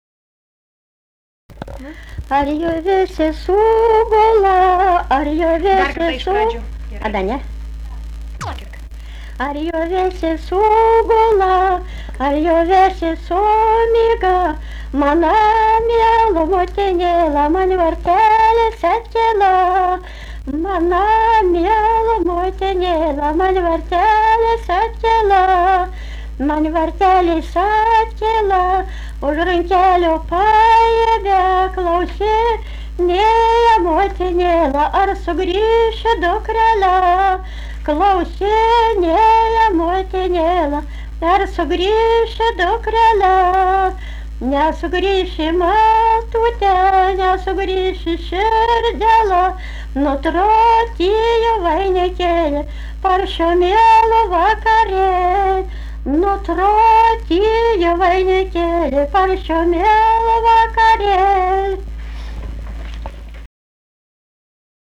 daina, vestuvių
Skaistgiriai
vokalinis